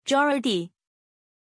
Pronunciación de Geordie
pronunciation-geordie-zh.mp3